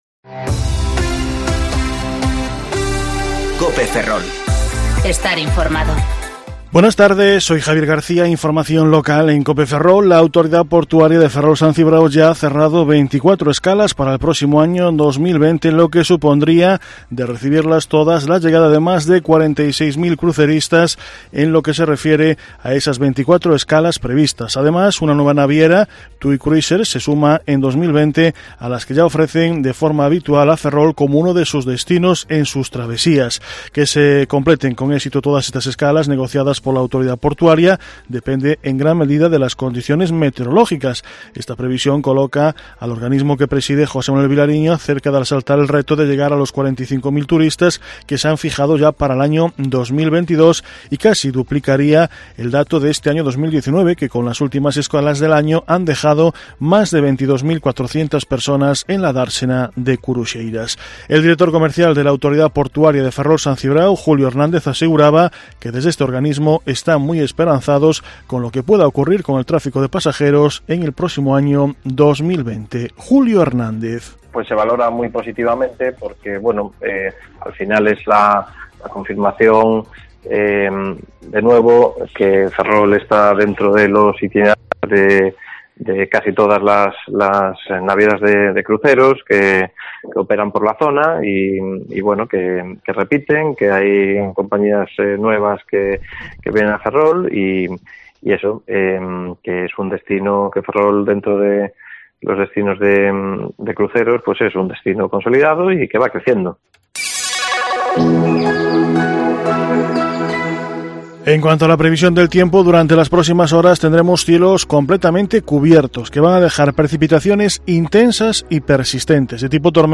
Informativo Mediodía Cope Ferrol 18/12/2019 (De 14,20 a 14,30 horas)